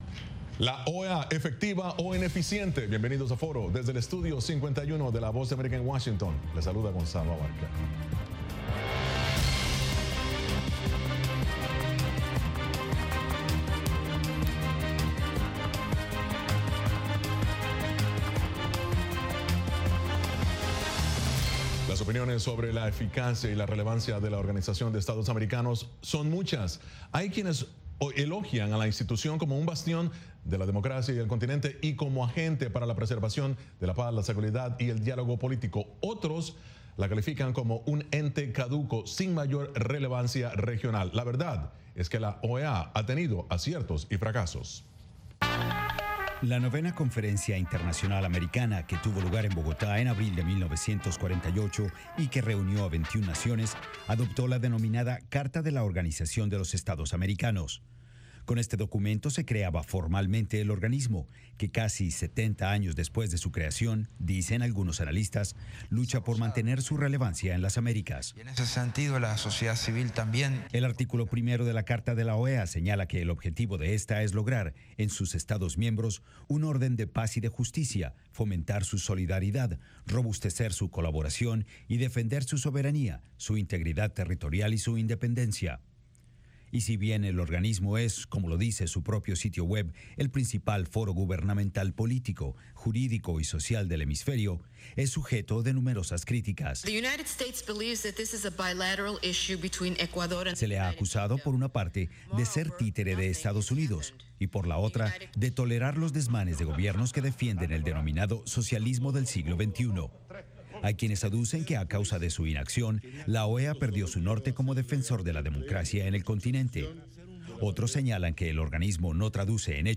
Los artistas de la música country se dan cita en este espacio para exponer sus éxitos y compartir algunas noticias de este genero.